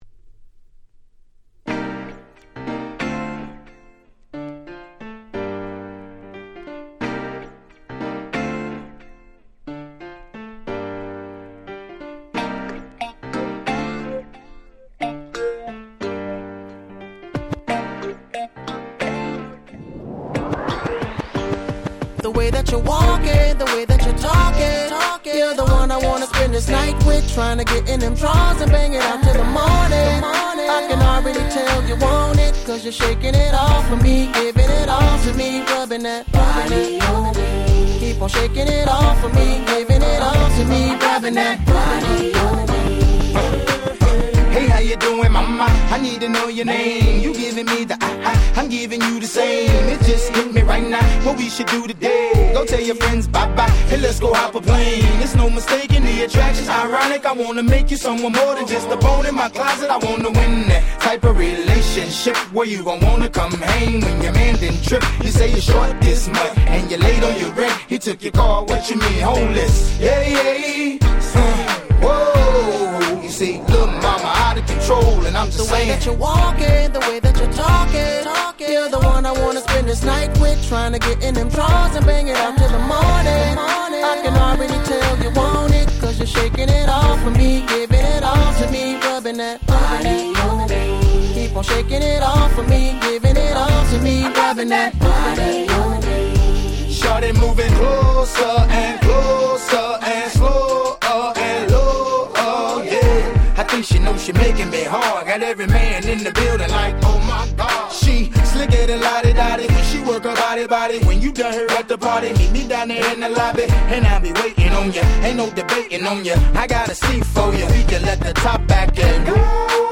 08' Super Hit Hip Hop/R&B♪